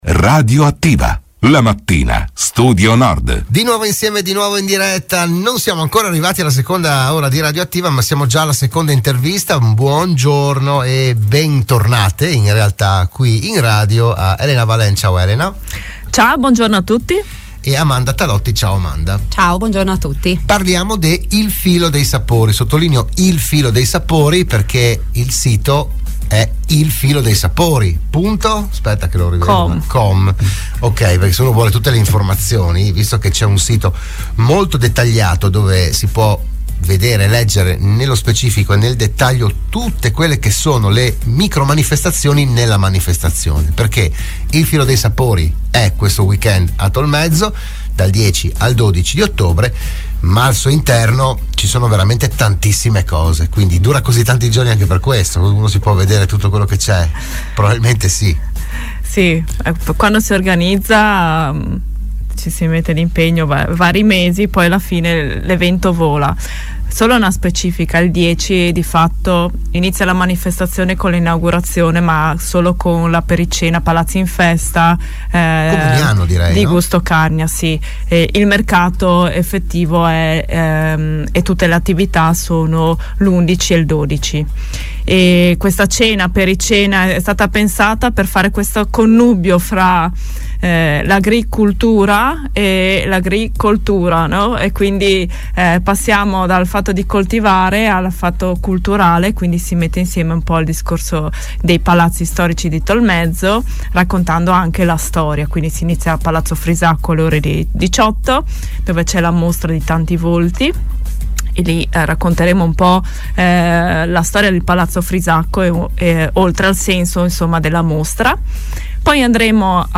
La kermesse ideata dalla Comunità di Montagna della Carnia è stata presentata a Radio Studio Nord